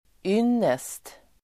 Uttal: [²'yn:est]